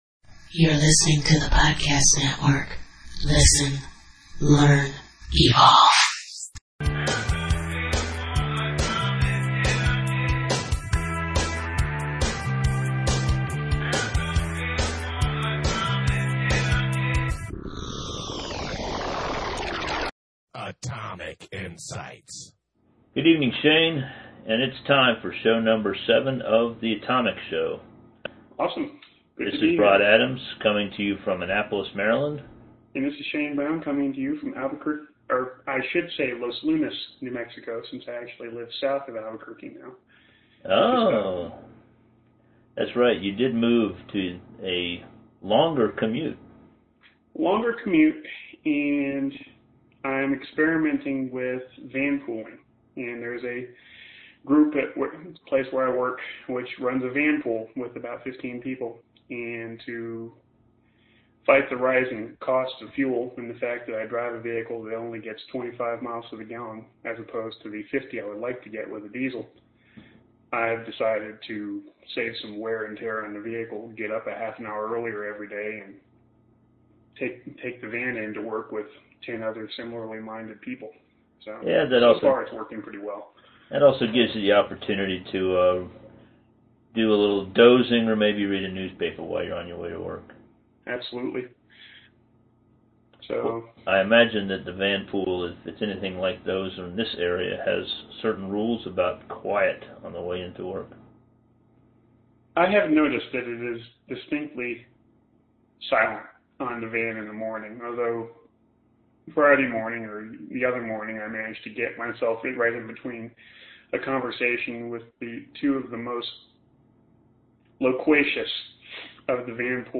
We are especially interested in comments about audio quality – so far no complaints, but that is not always grounds for celebration.